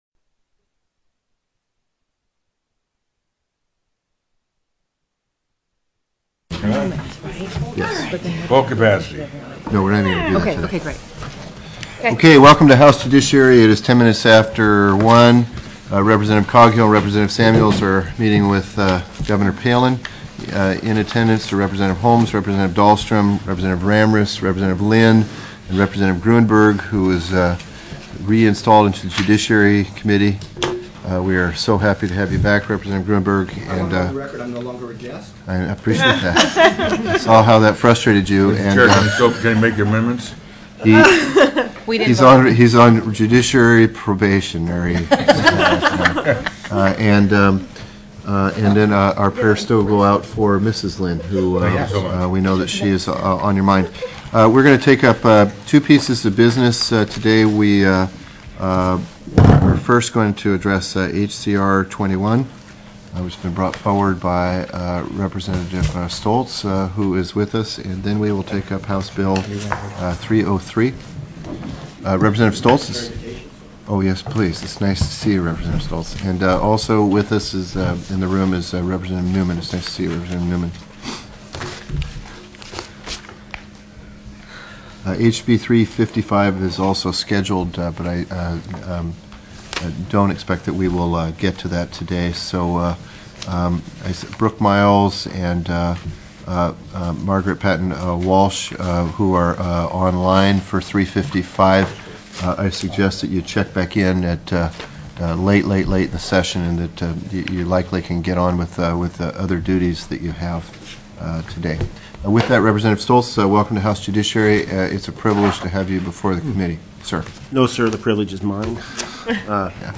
02/13/2008 01:00 PM House JUDICIARY
HOUSE JUDICIARY STANDING COMMITTEE